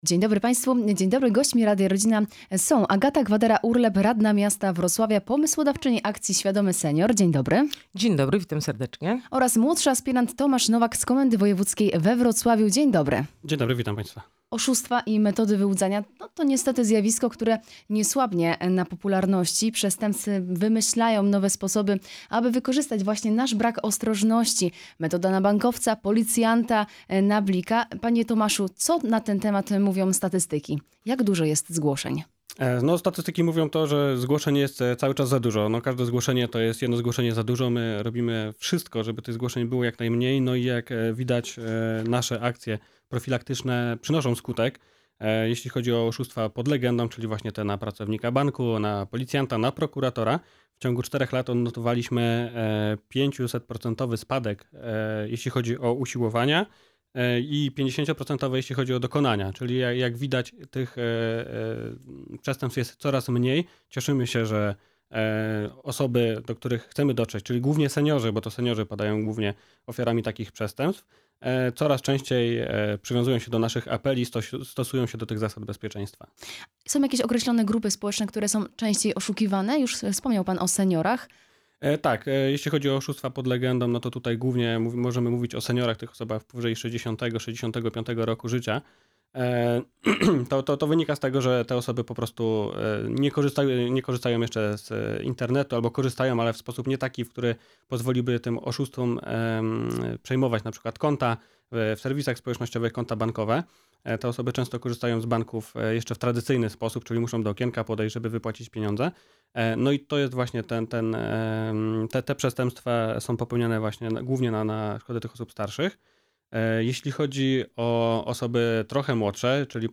Nie tylko „na wnuczka" – brońmy się przed oszustami [Poranny Gość] - Radio Rodzina
Poranna-Rozmowa.mp3